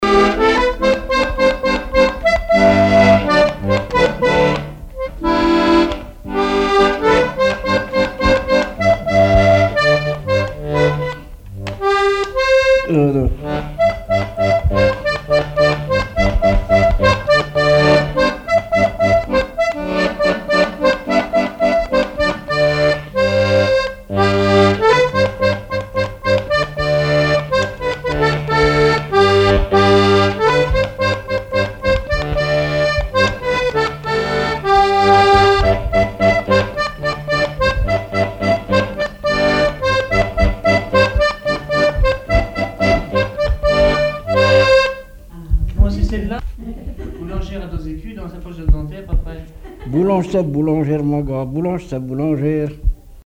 Rondes enfantines à baisers ou mariages
danse : ronde : boulangère ;
Chansons et répertoire du musicien sur accordéon chromatique
Pièce musicale inédite